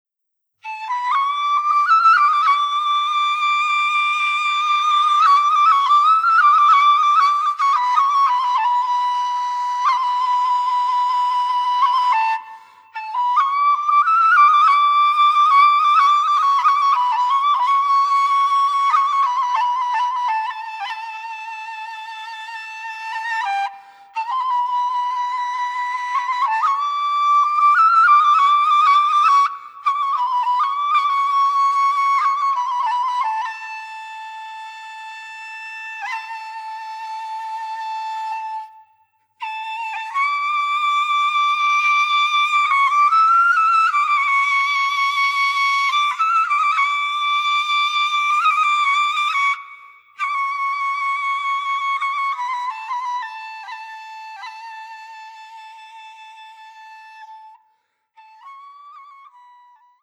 traditional Bulgarian kaval music
Slow Song